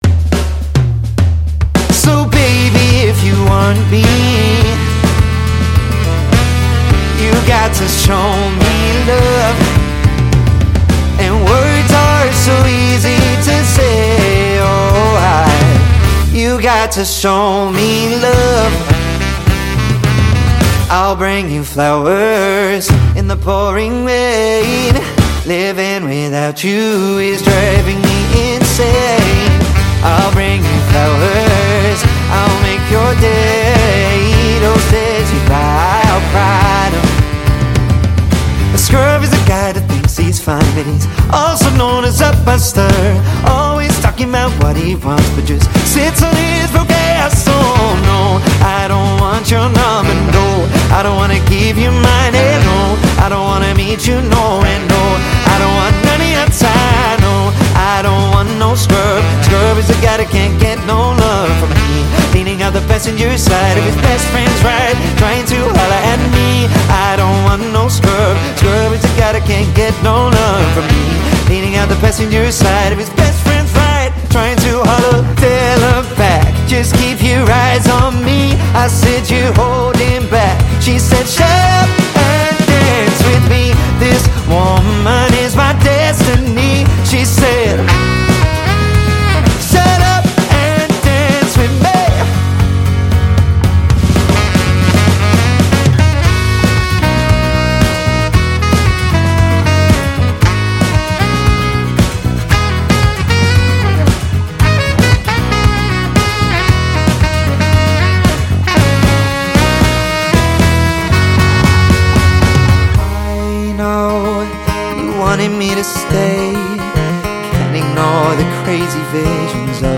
• 4-piece
Male Vocals/Guitar, Bass, Drums, Sax